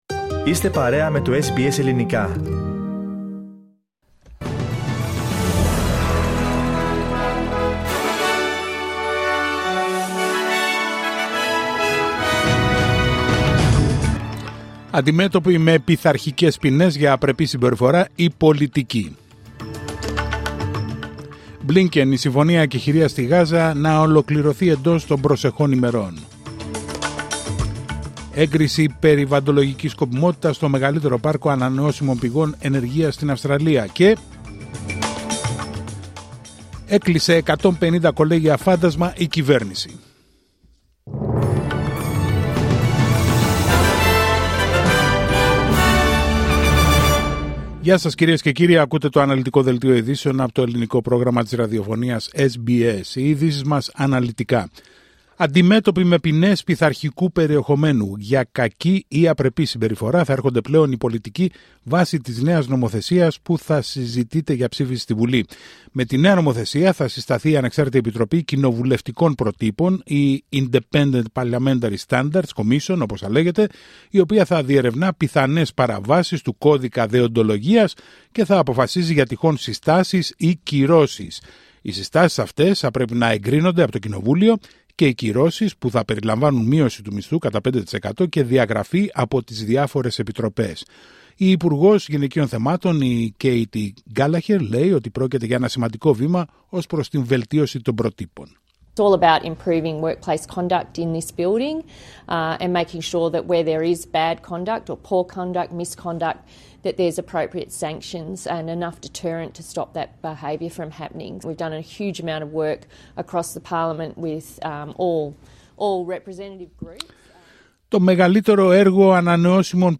Δελτίο ειδήσεων Τετάρτη 21 Αυγούστου 2024